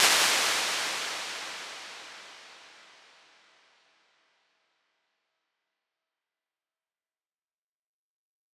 Lexicon-P441 - 8.6 Seconds C.wav